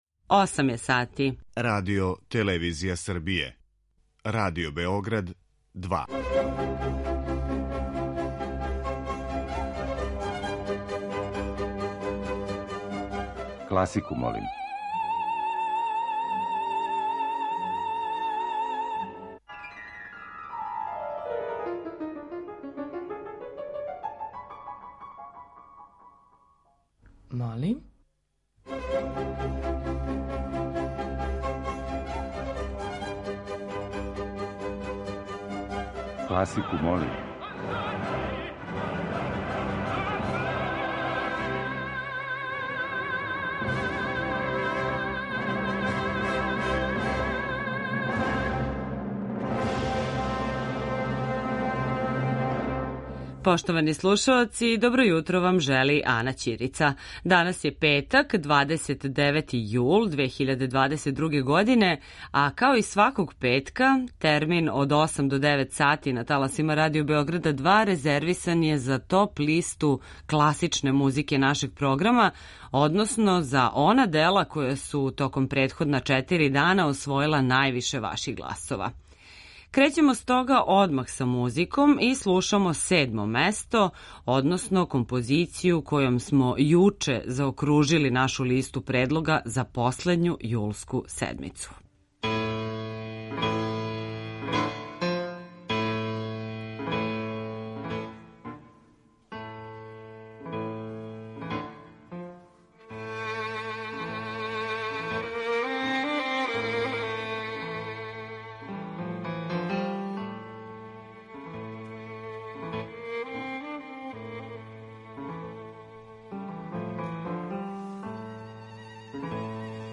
Група аутора Стилски и жанровски разноврсни циклус намењен и широком кругу слушалаца који од понедељка до четвртка гласају за топ листу недеље.
После сабирања гласова које смо примили од понедељка до четвртка, емитујемо топ-листу класичне музике Радио Београда 2, односно композиције које су се највише допале слушаоцима у последњој седмици јула.